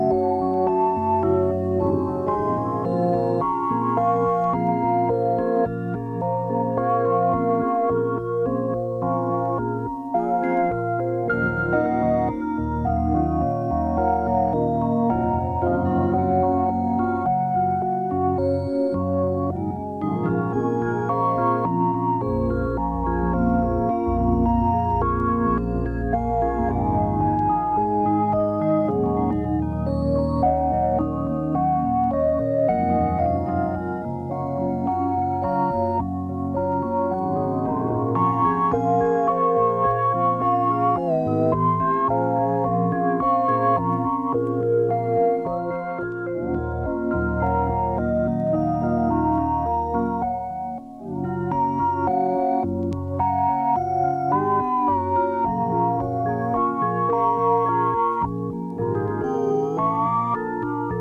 Electronix Techno